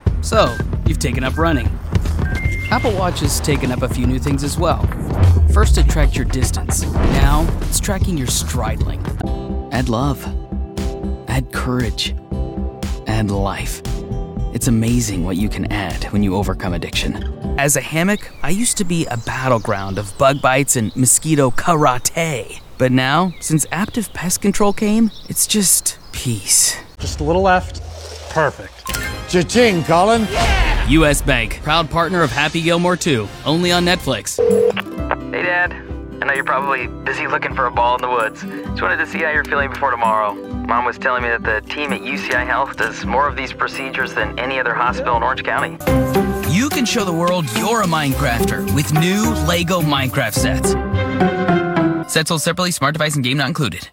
Do you need a youthful male voice actor for your video?